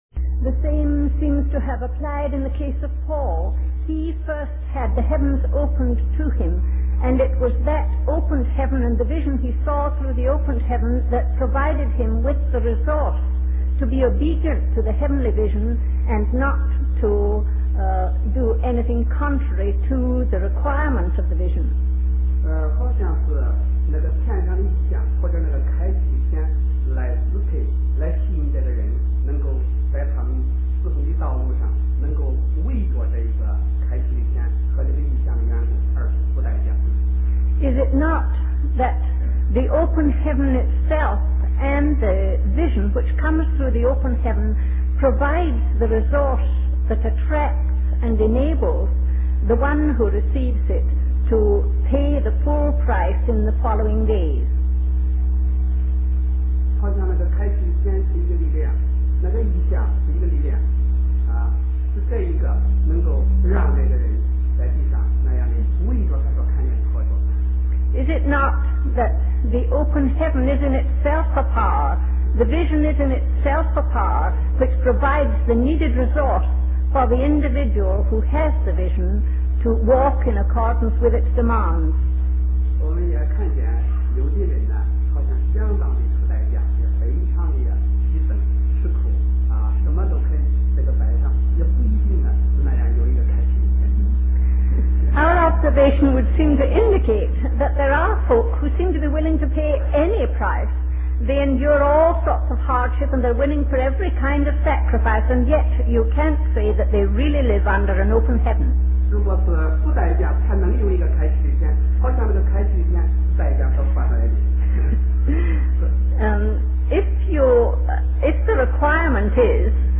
The Persistent Purpose Of God #14: Questions and Answers
Taiwan, Republic Of China